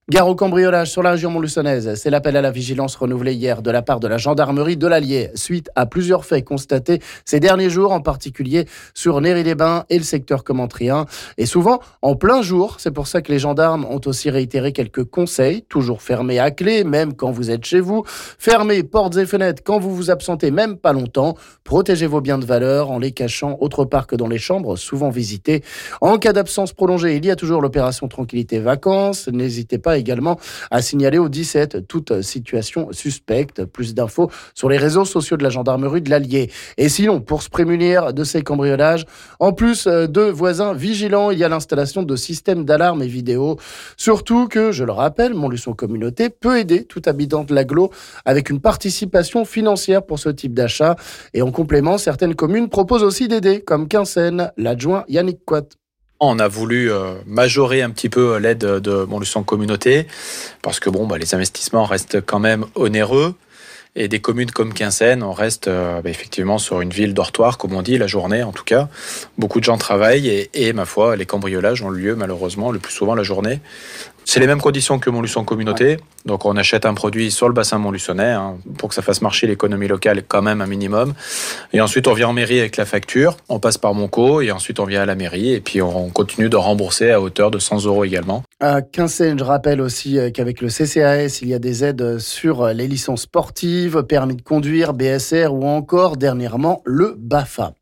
On écoute ici l'adjoint quinssainois Yannick Coite...